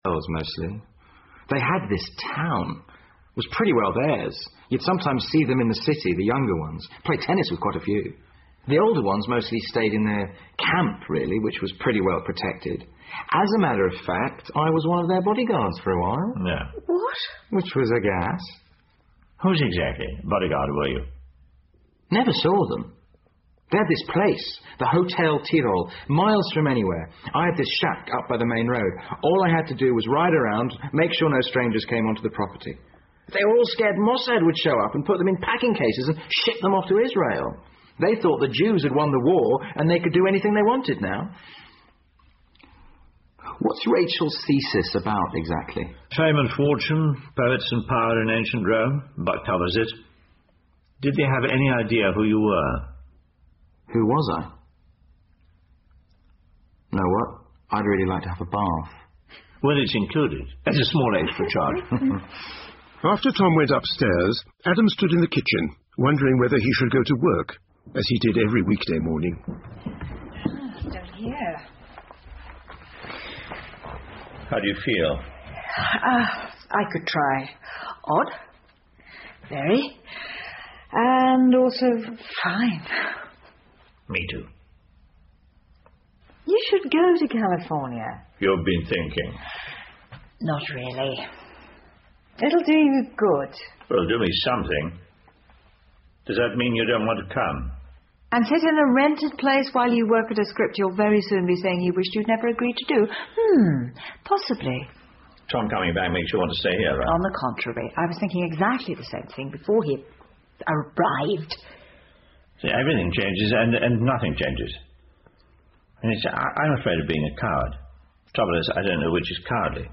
英文广播剧在线听 Fame and Fortune - 43 听力文件下载—在线英语听力室